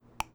menu up down.wav